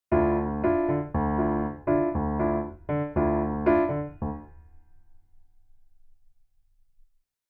Step 2: chord rhythm
Next, we’ll add in a basic samba rhythm in the right hand:
How-Insensative-bassline-chords.mp3